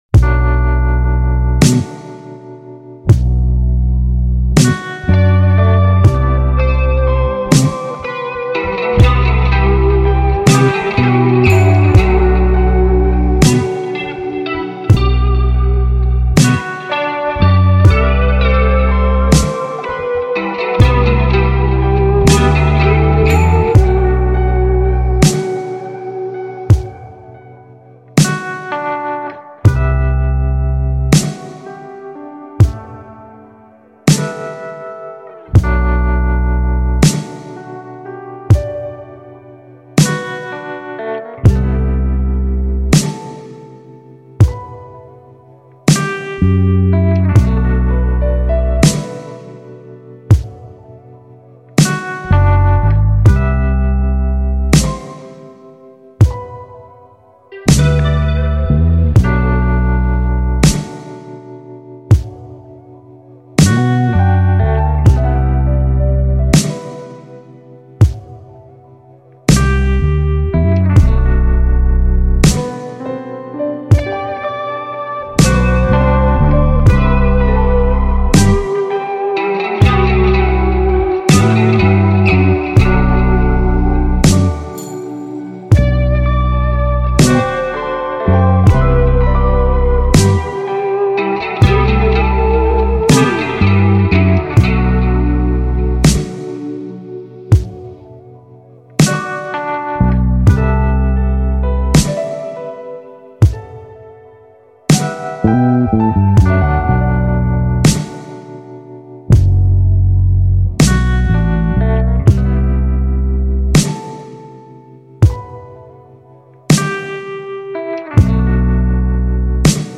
official instrumental
R&B Instrumentals